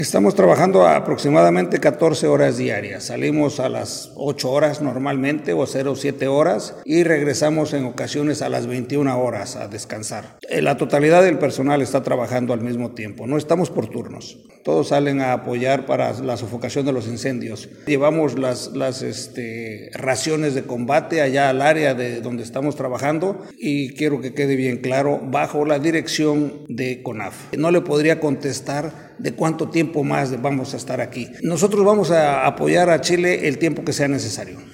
En conversación con La Radio, el general brigadier agradeció el apoyo y colaboración de la ciudadanía en esta contingencia.